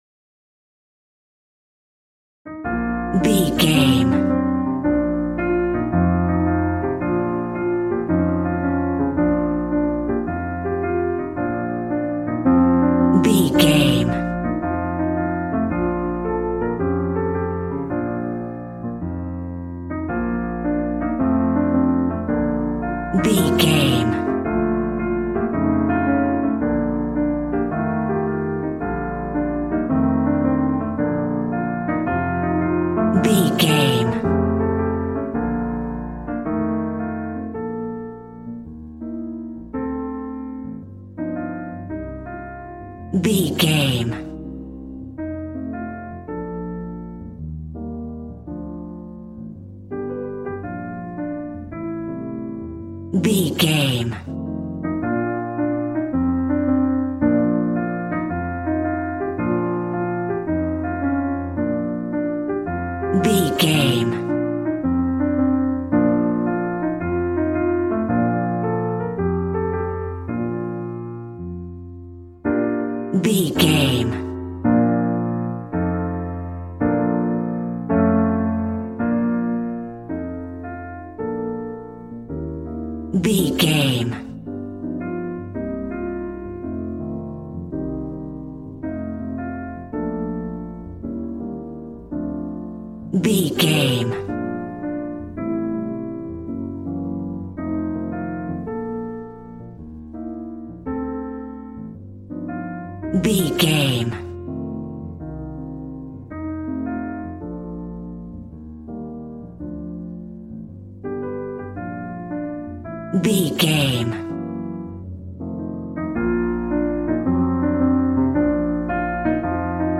Ionian/Major
cool
piano